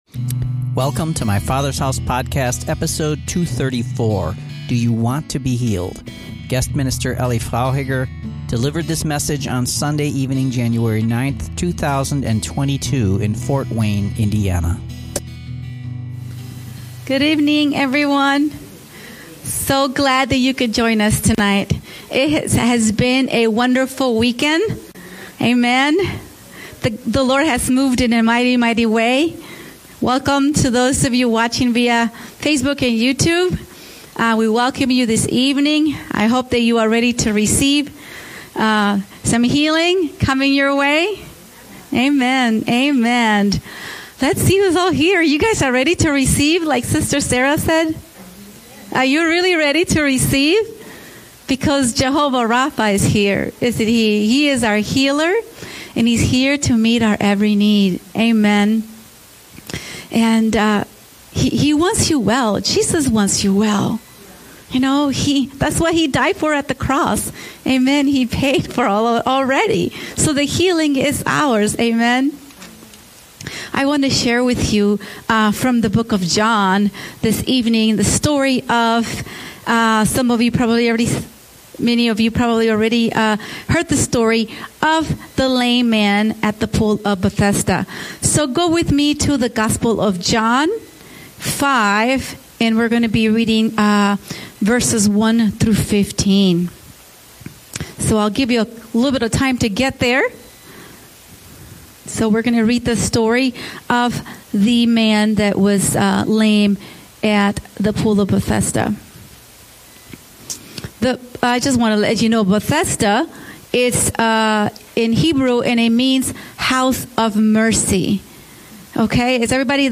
Bonus Sunday evening teaching on the subject of healing